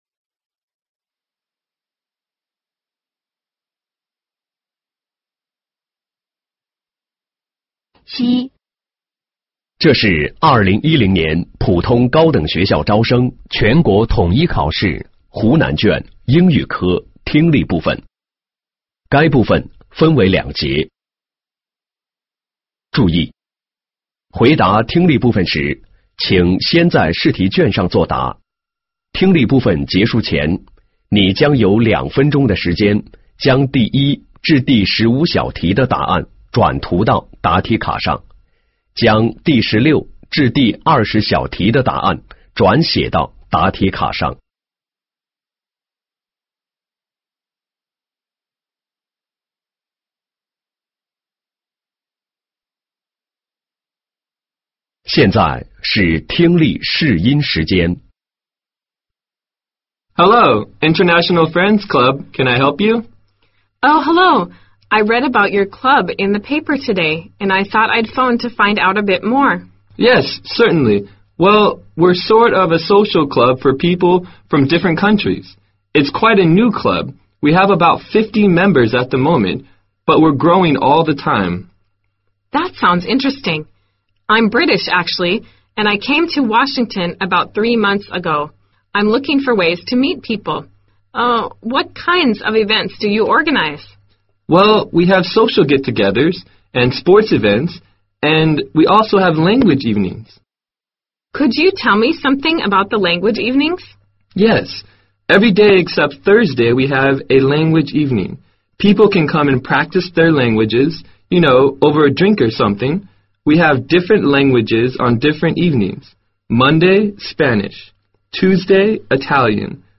2010年高考英语听力